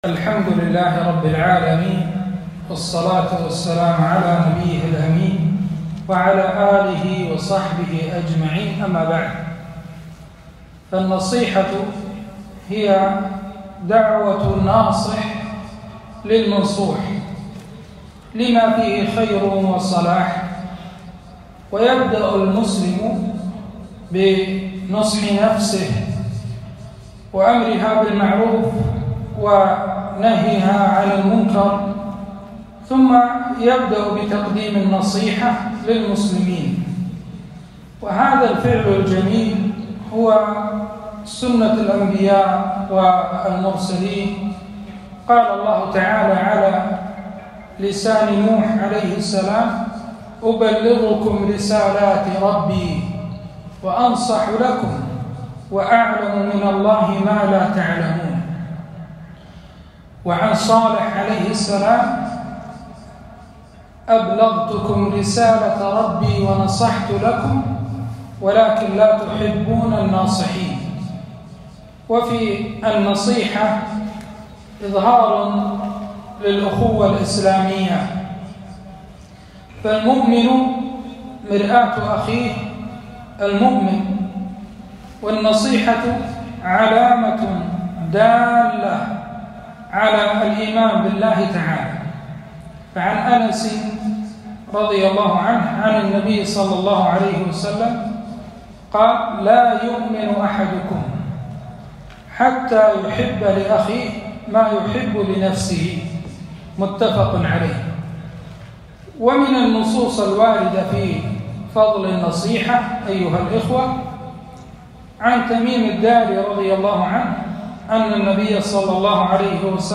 كلمة - فقه النصيحة وآدابها